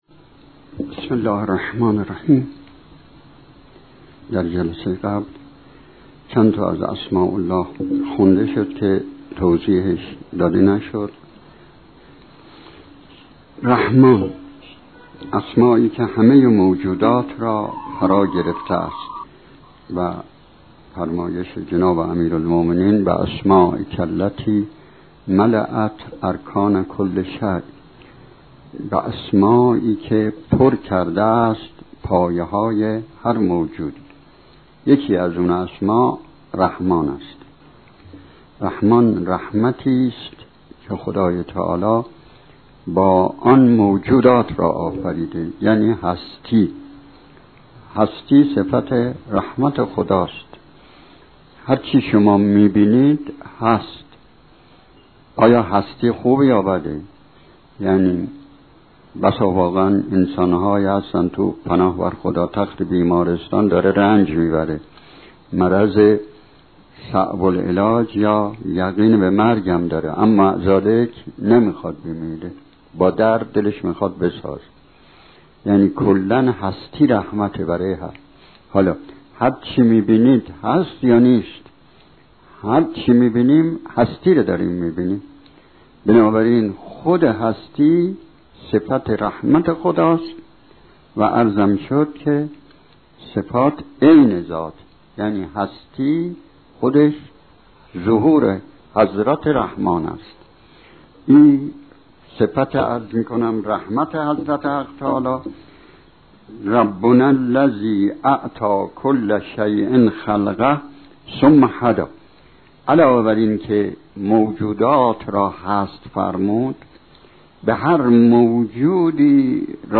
جلسات سخنرانی